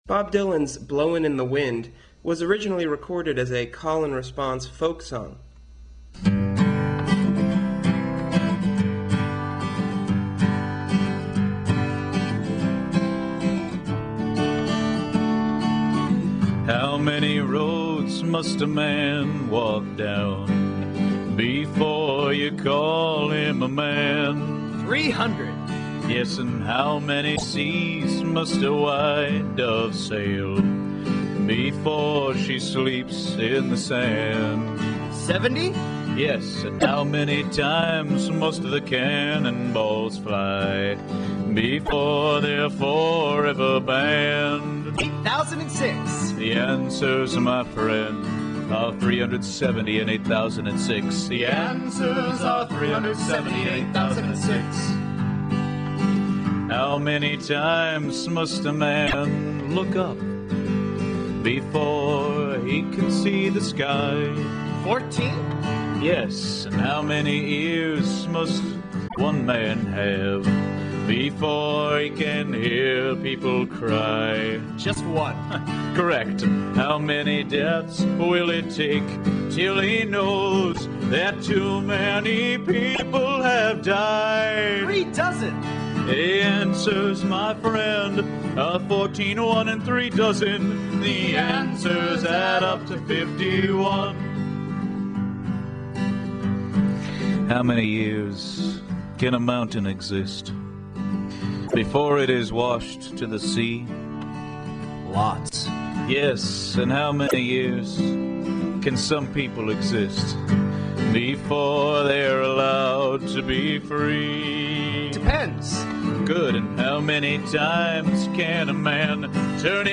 A performance featuring the original lyrics